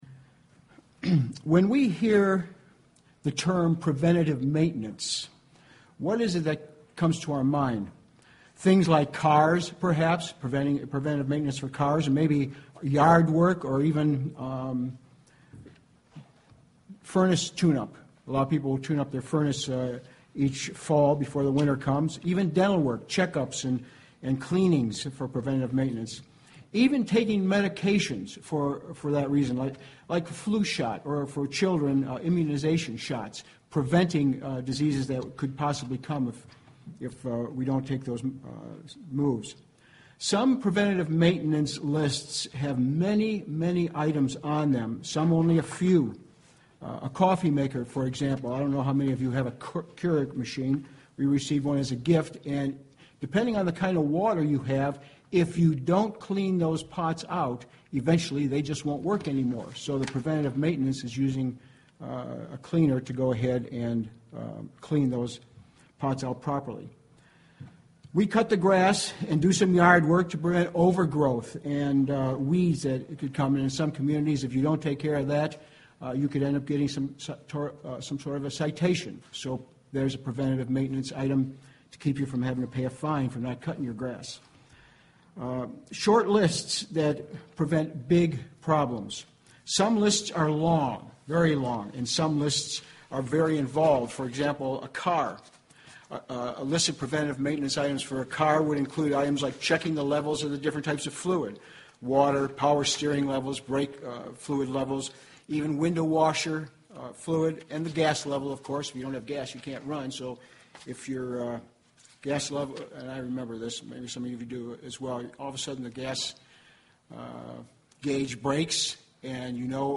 Given in Cleveland, OH
UCG Sermon Studying the bible?